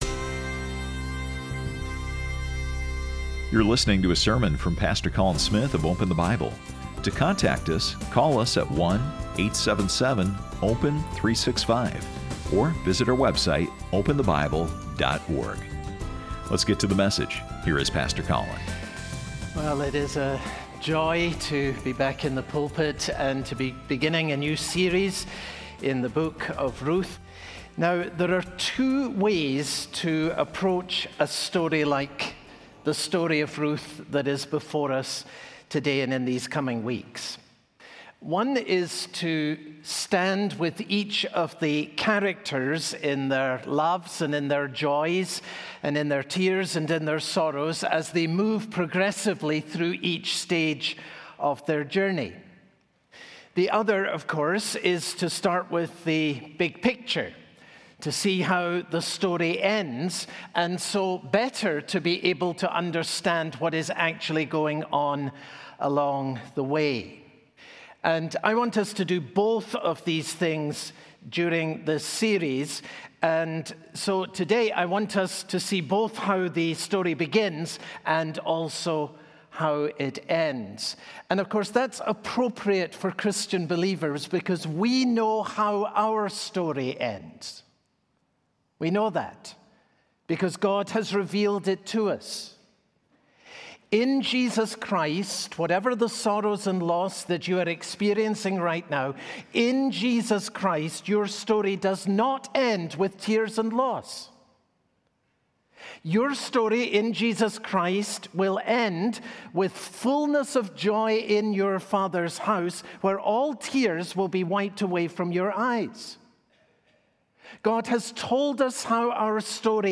Sermon Details Date Sep 10